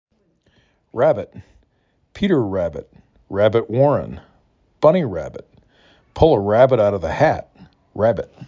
6 Letters, 2 Syllables
'rab bit
r a b ə t
' = primary stress ə = schwa